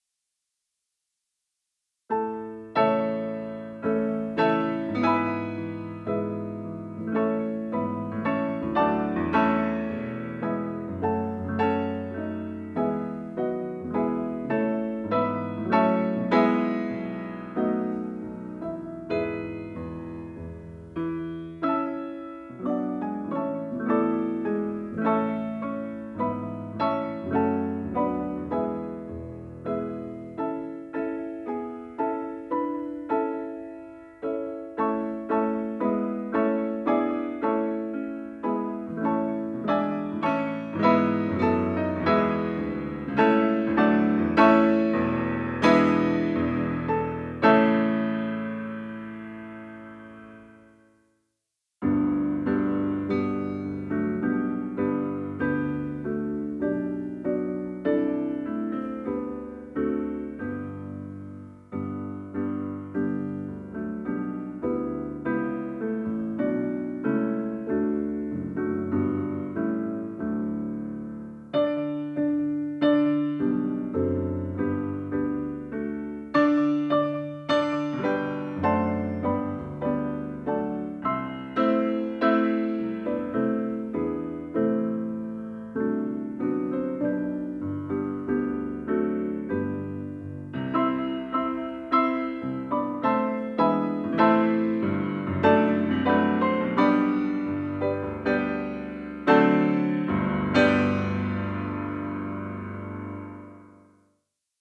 chants traditionnels